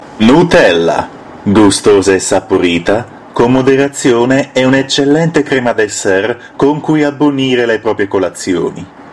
Versatile voice, ready to serve, proper, honest, dutyful, no time-waster.
Sprechprobe: Werbung (Muttersprache):